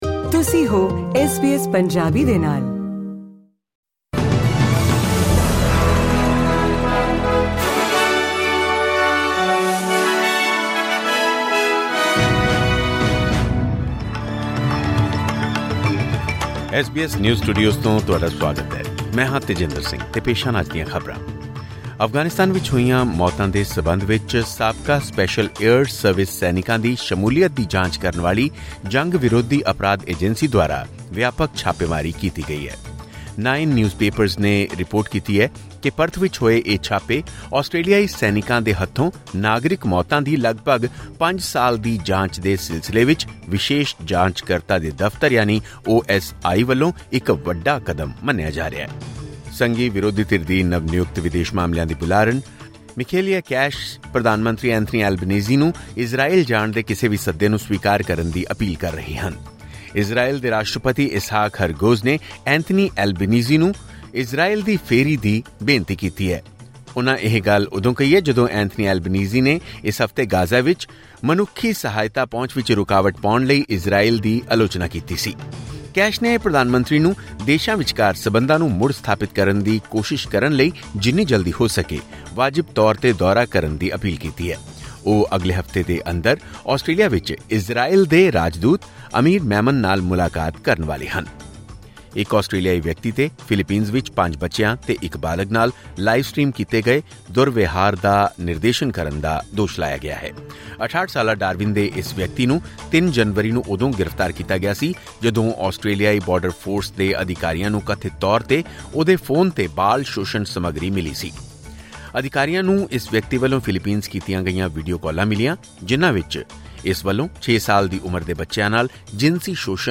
ਖਬਰਨਾਮਾ: ਆਸਟ੍ਰੇਲੀਆਈ ਆਦਮੀ ਉੱਤੇ ਫਿਲੀਪੀਨਜ਼ ਵਿੱਚ 5 ਬੱਚਿਆਂ ਨਾਲ ਕੀਤੇ ਗਏ ਦੁਰਵਿਵਹਾਰ ਦਾ ਲਾਈਵ ਸਟ੍ਰੀਮ ਨਿਰਦੇਸ਼ਨ ਕਰਨ ਦਾ ਦੋਸ਼